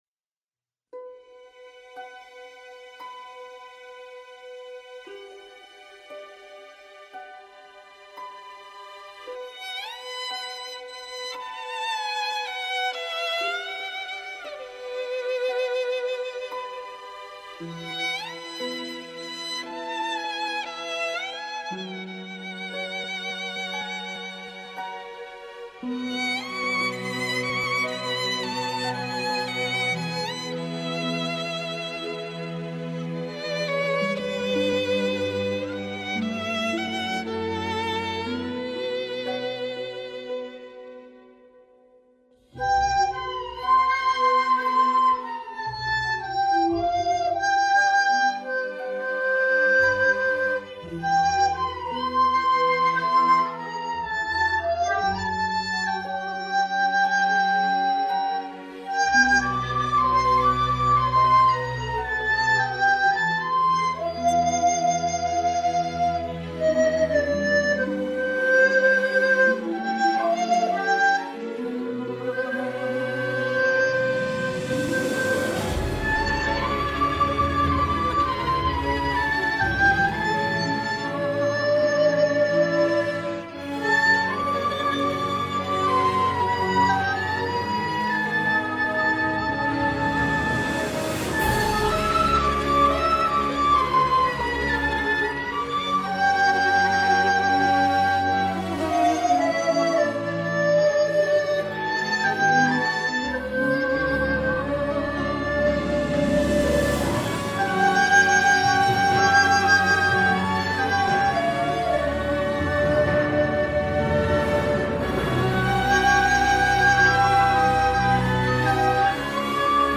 伴奏是消音的，效果不太好。吹得很粗糙，贴上来天热消消暑吧😊).
这个伴奏已经调过，可能还不够。